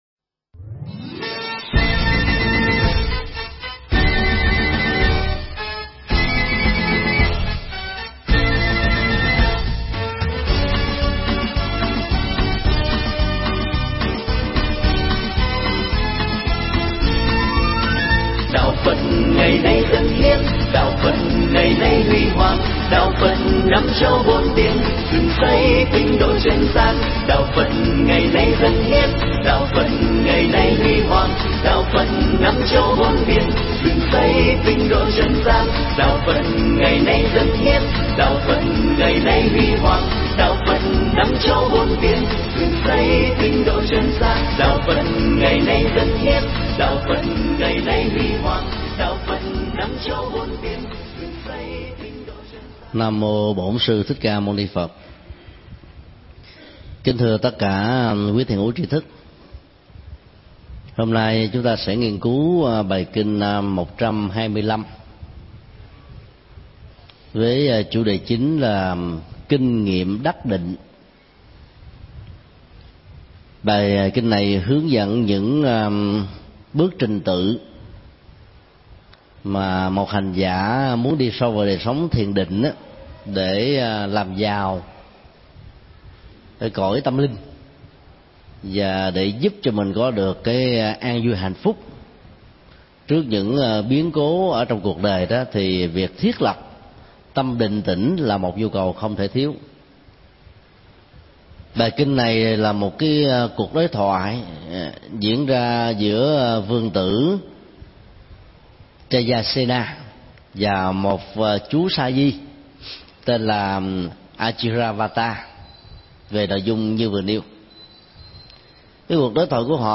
Tải mp3 Pháp thoại Kinh Trung Bộ 125 (Kinh Điều Ngự Địa) – Kinh nghiệm đắc định
giảng tại Chùa Xá Lợi